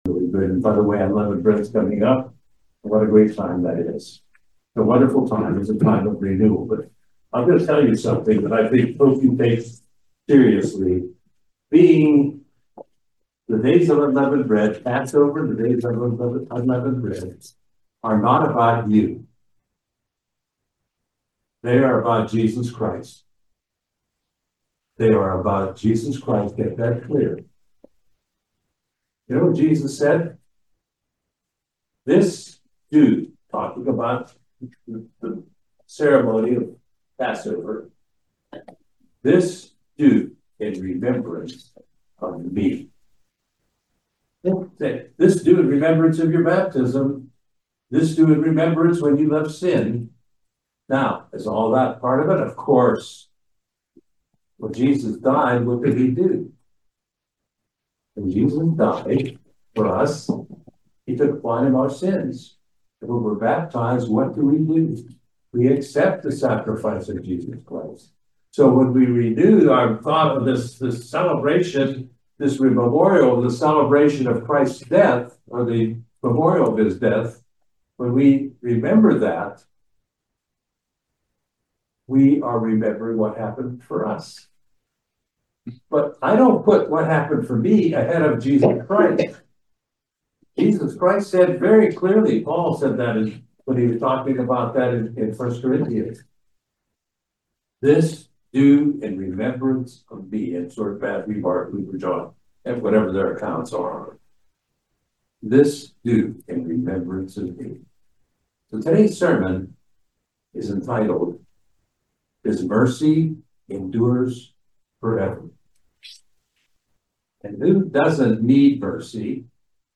Join us for this eye opening video sermon on Mercy and Graciousness. See what God's Holy Scriptures have to say about this subject.